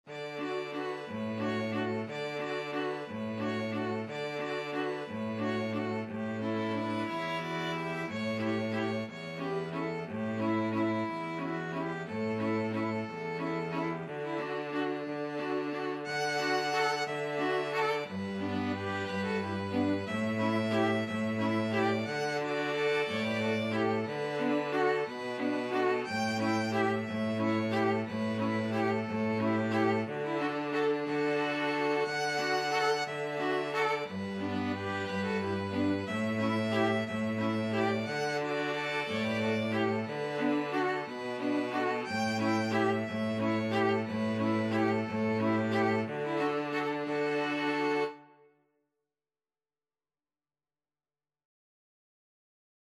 Violin 1Violin 2ViolaCello
3/4 (View more 3/4 Music)
D major (Sounding Pitch) (View more D major Music for String Quartet )
Elegantly . = c.60
String Quartet  (View more Easy String Quartet Music)
Traditional (View more Traditional String Quartet Music)
cielito_lindo_STRQ.mp3